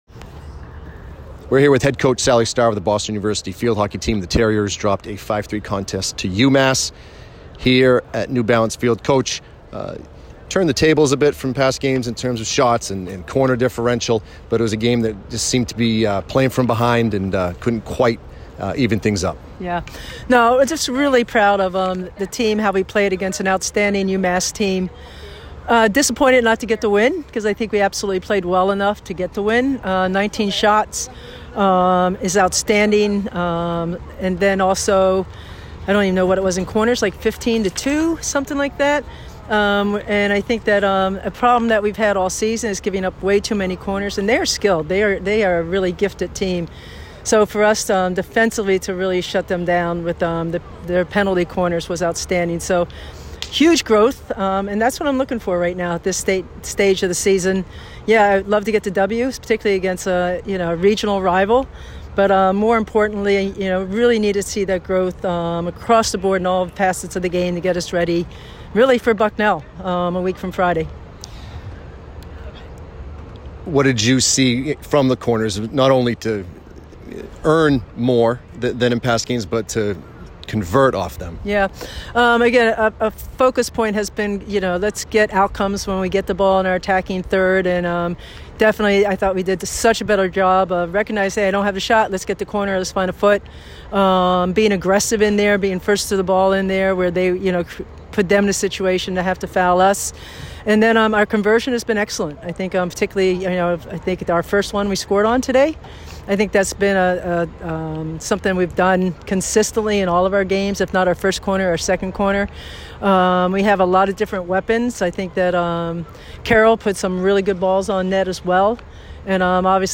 Massachusetts Postgame Interview